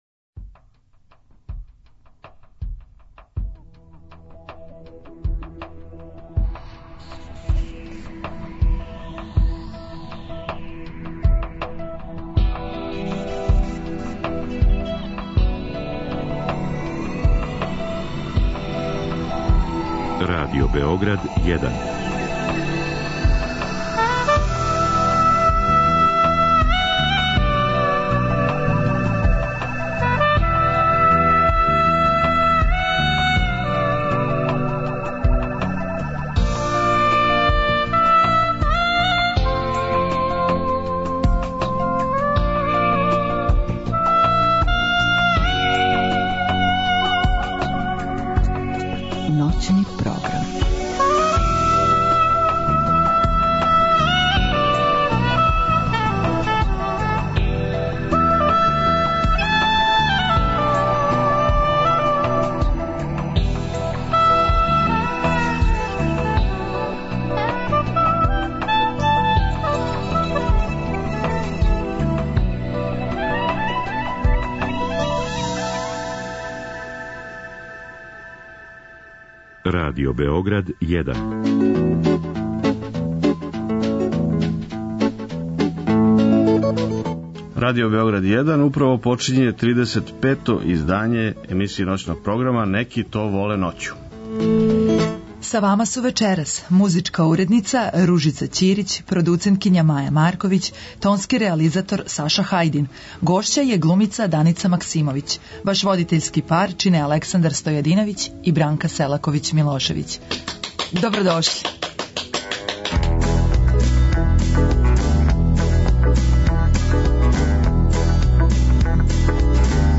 Гост ове недеље је глумица која својом харизмом, талентом, маестралним улогама плени уметничком сценом Балкана, Даница Максимовић. Разговараћемо о новим филмским улогама, глумачким почецима И непресушној љубави према позоришту и публици.